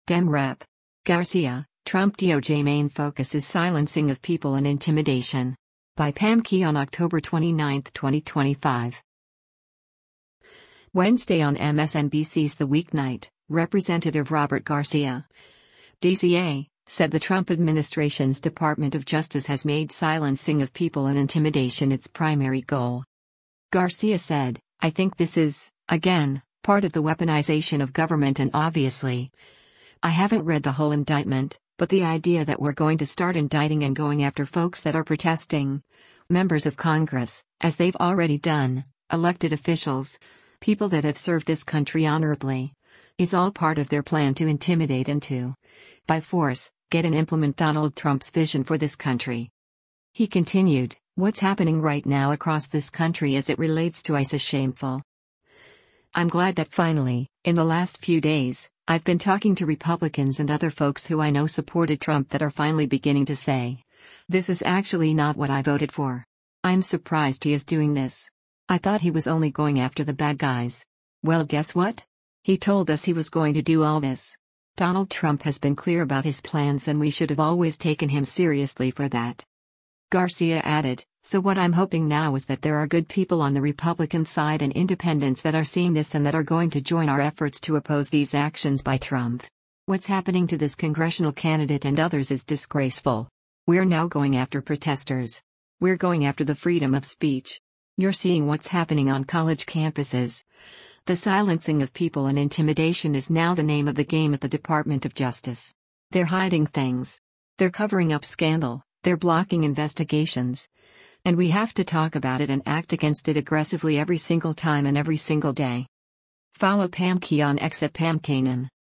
Wednesday on MSNBC’s “The Weeknight,” Rep. Robert Garcia (D-CA) said the Trump administration’s Department of Justice has made “silencing of people and intimidation” its primary goal.